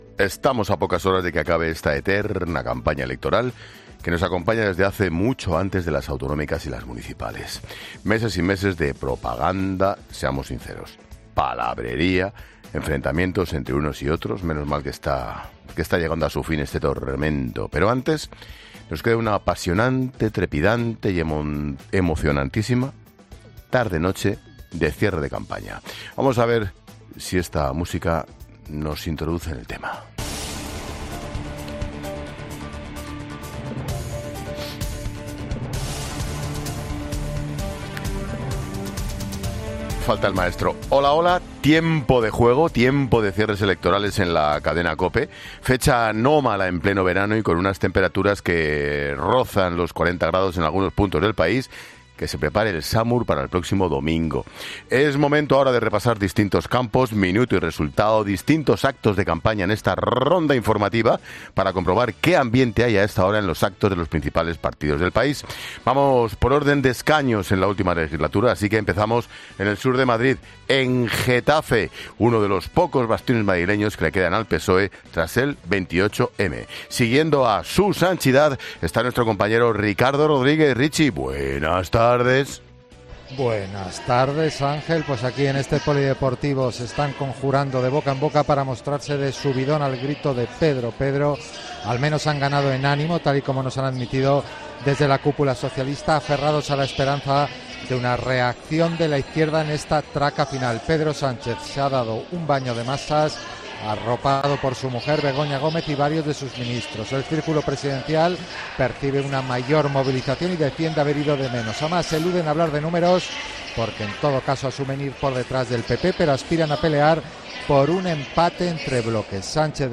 Escucha el acto del cierre de campaña de Vox desde la Plaza de Colón en Madrid